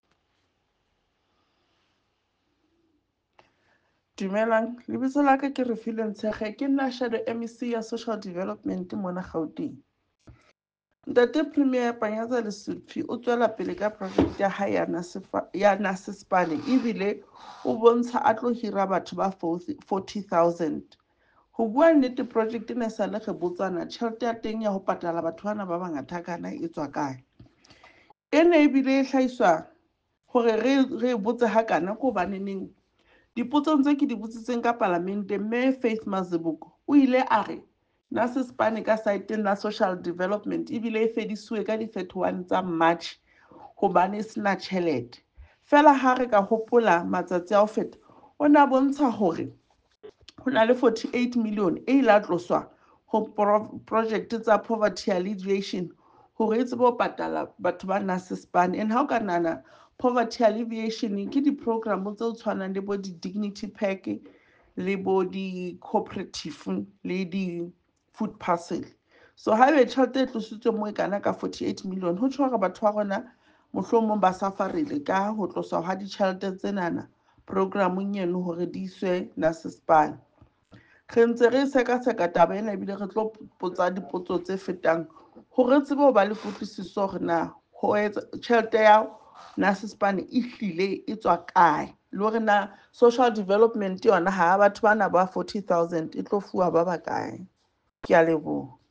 Issued by Refiloe Nt’sekhe MPL – DA Gauteng Shadow MEC for Social Development
Sesotho soundbites by Refiloe Nt’sekhe MPL.